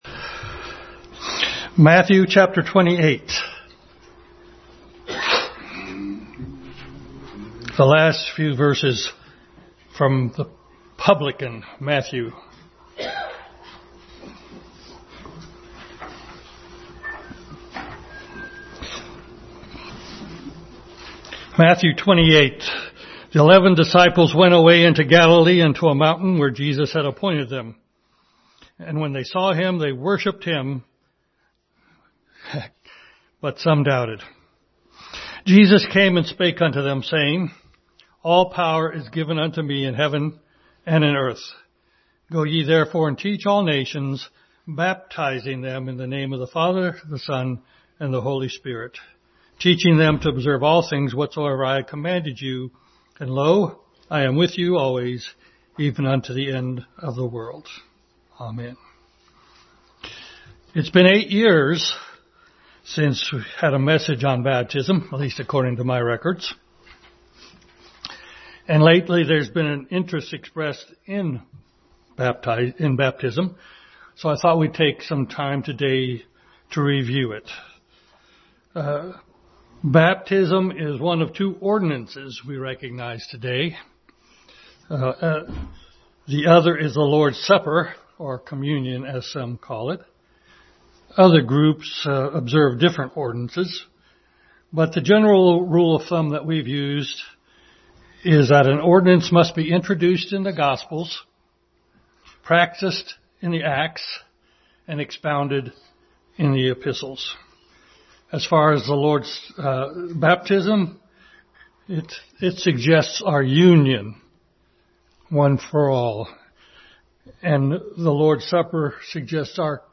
Ordinance of Baptism Passage: Matthew 28:16-20 Service Type: Family Bible Hour